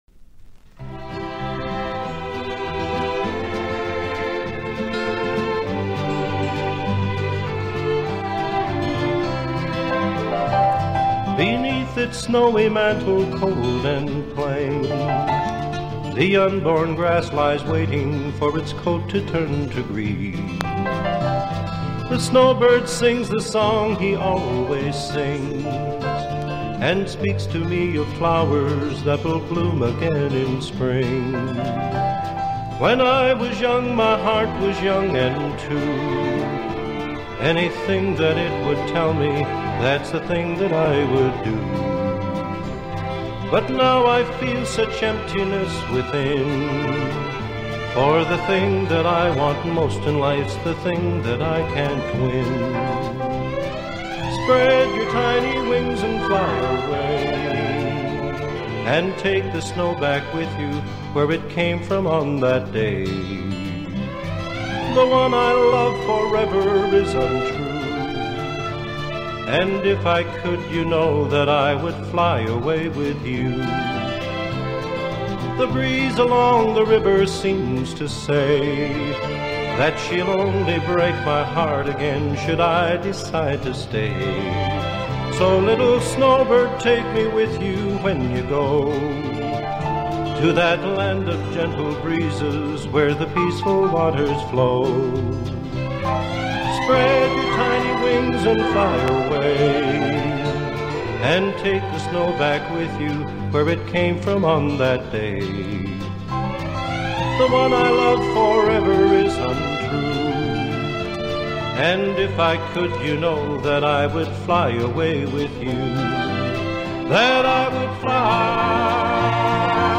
Program Type: Music Speakers